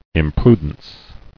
[im·pru·dence]